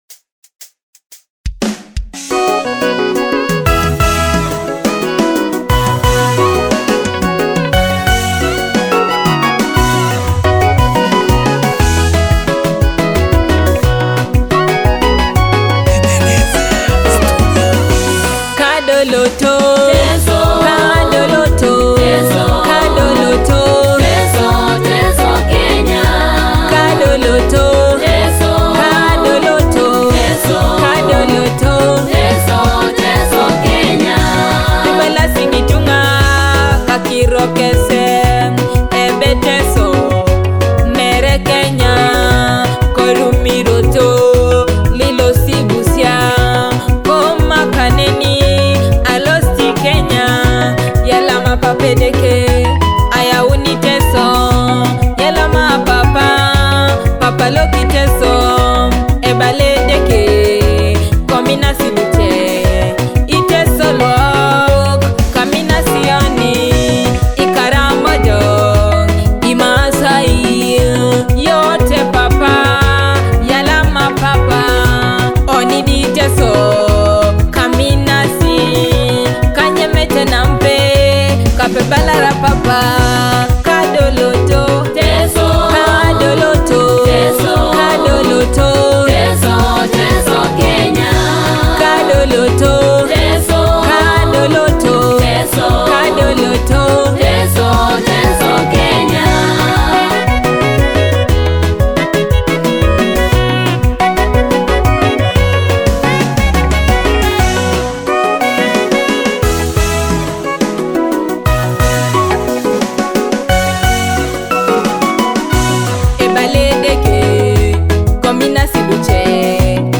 a captivating blend of rhythmic beats and powerful vocals.